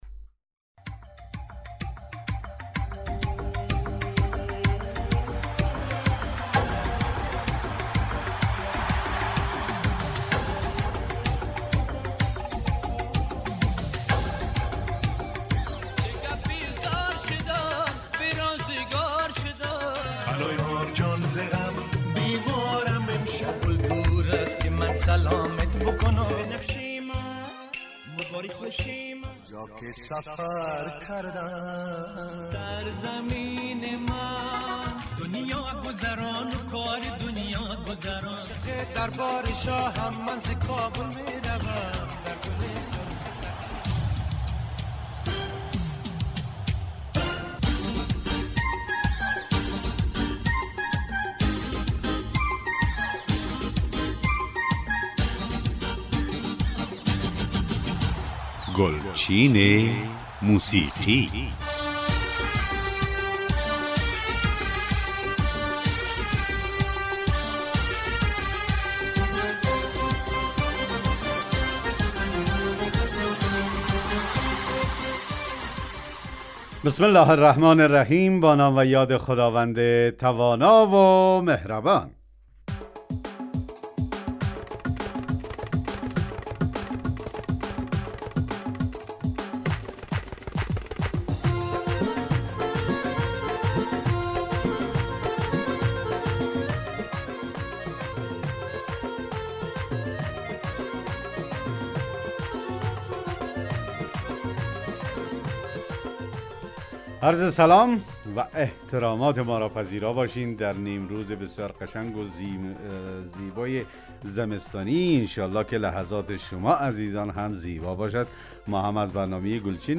ترانه های درخواستی